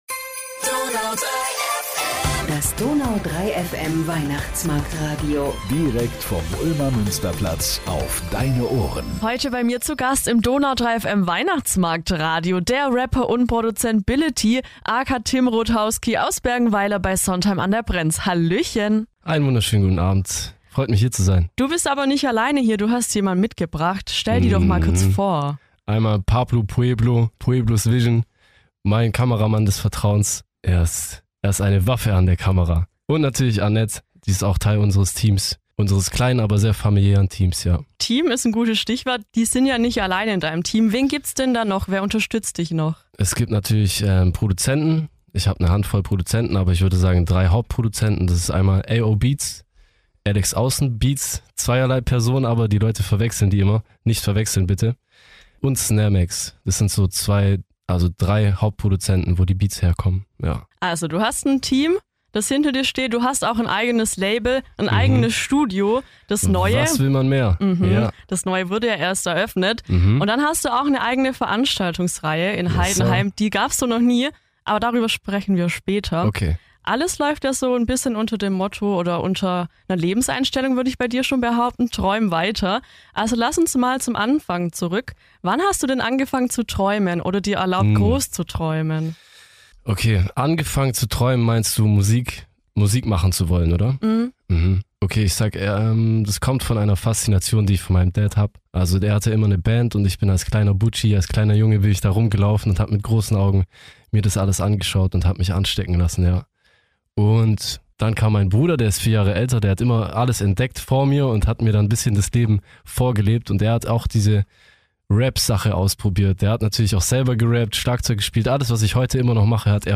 Im Interview geht es ums Träumen, den Mut einfach zu machen und nicht auf Andere zu hören und um das Leben auf dem Dorf.